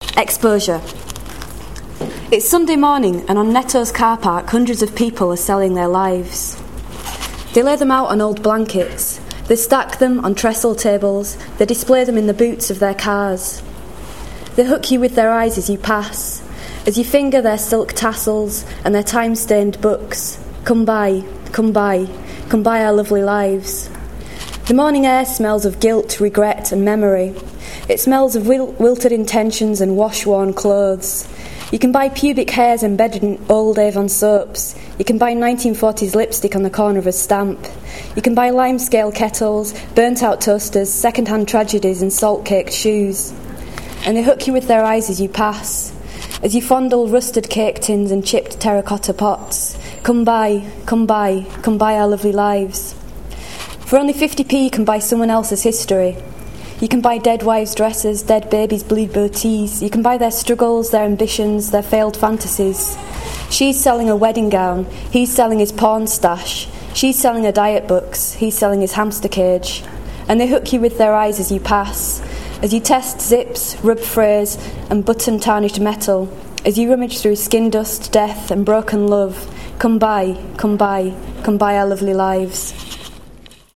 read a selection of poems